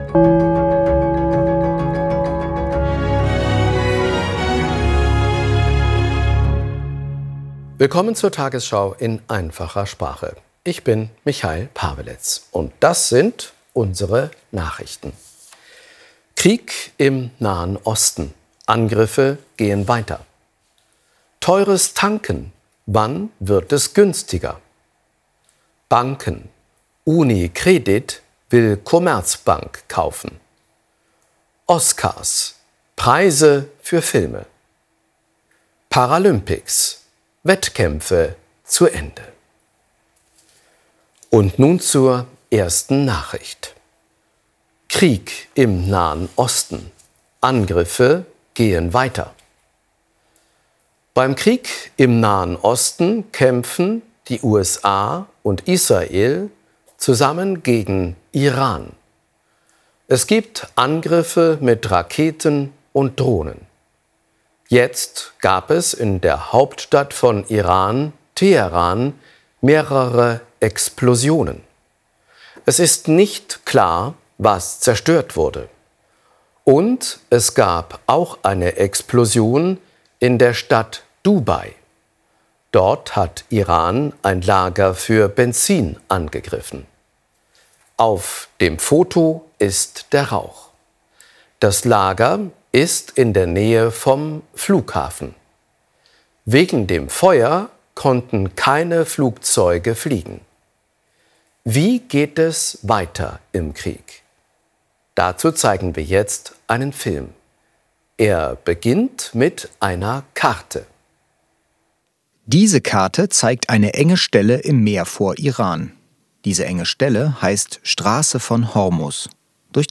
Bildung , Nachrichten